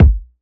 Dilla Kick 50.wav